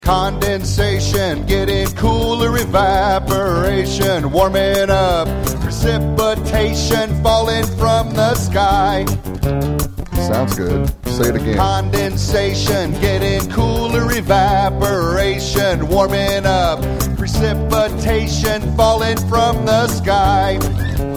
Science Song Lyrics and Sound Clip